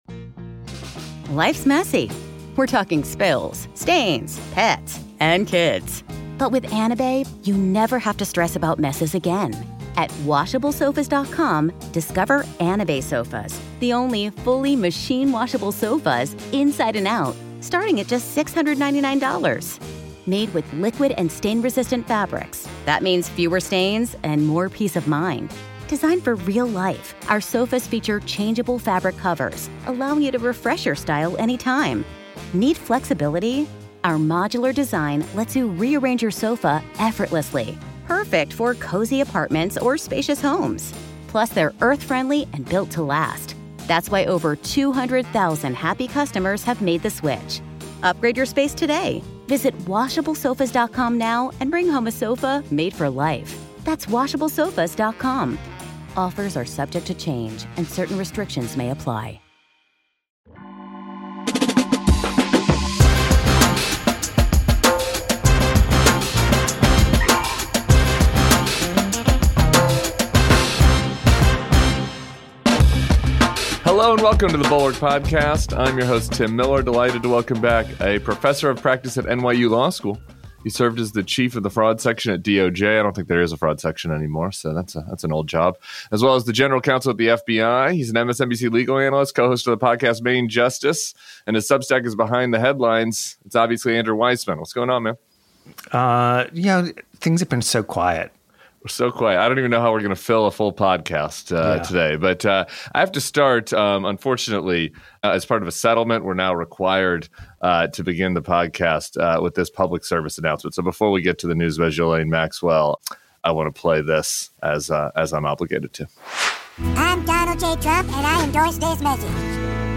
Andrew Weissmann joins Tim Miller for the weekend pod.